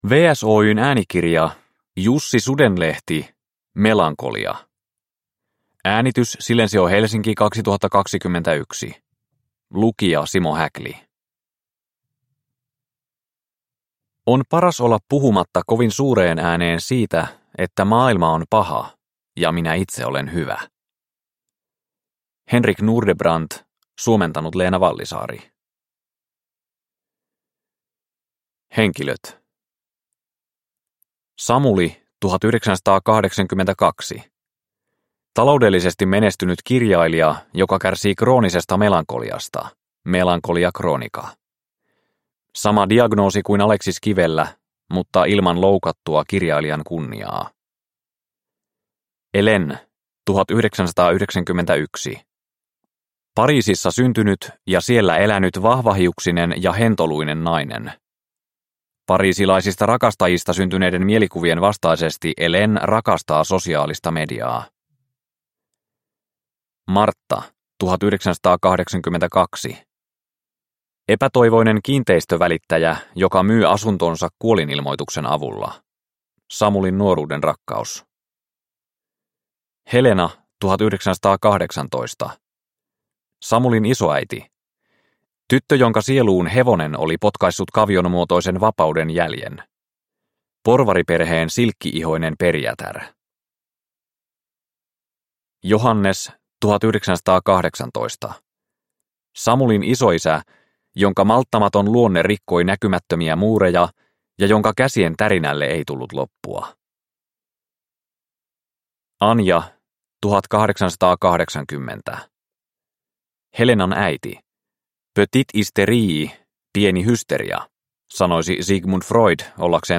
Melankolia – Ljudbok – Laddas ner